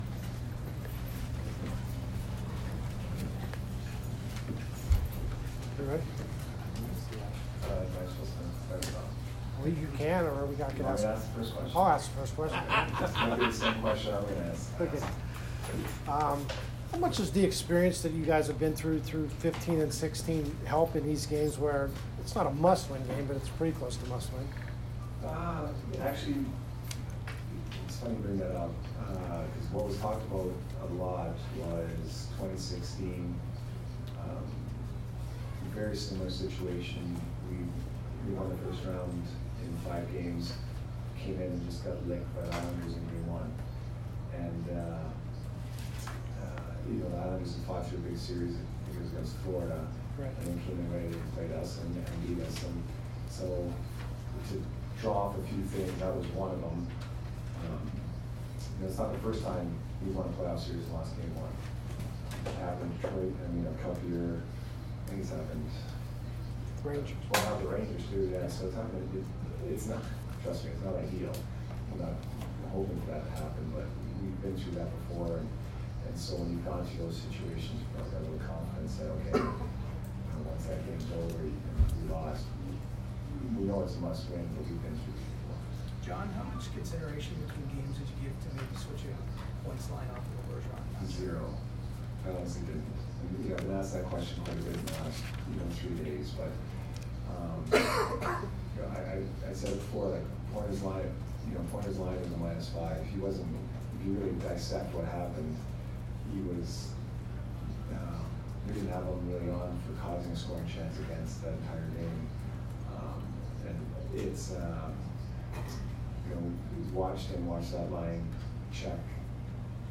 Jon Cooper post-game 4/30